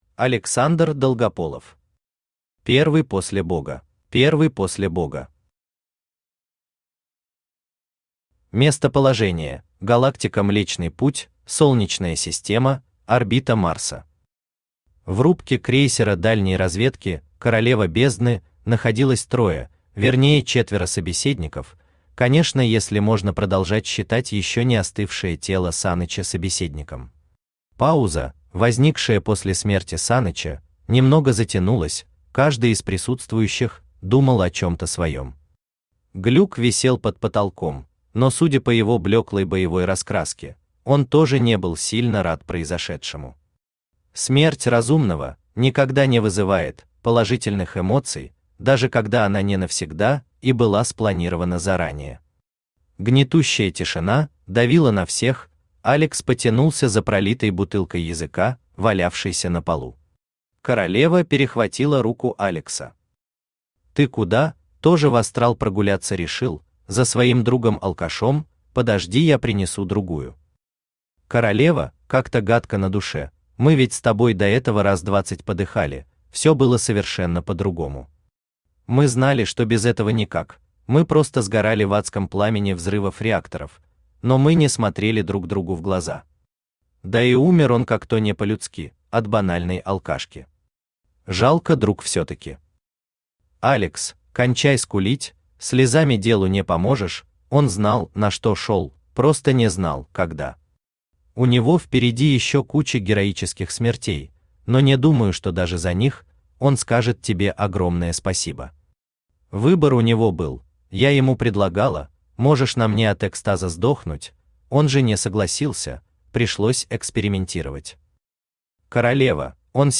Аудиокнига Первый после бога | Библиотека аудиокниг
Aудиокнига Первый после бога Автор Александр Долгополов Читает аудиокнигу Авточтец ЛитРес.